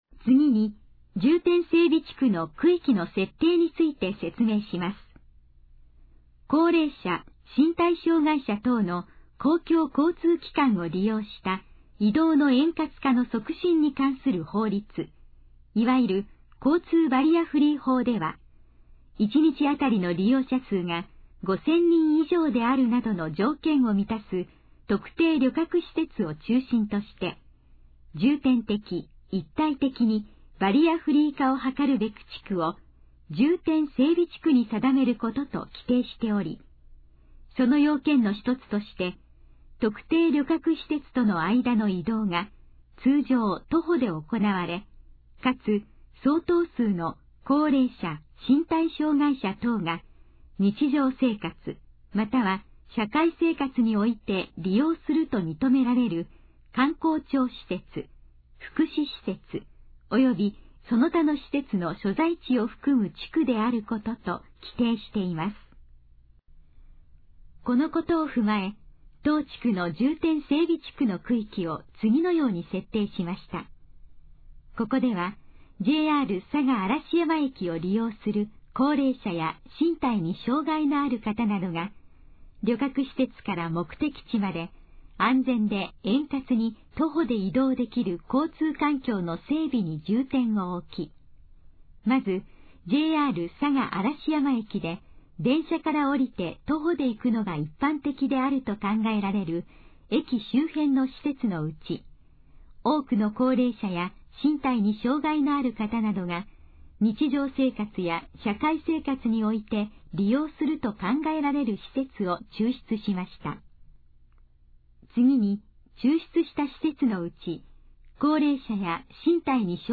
このページの要約を音声で読み上げます。
ナレーション再生 約398KB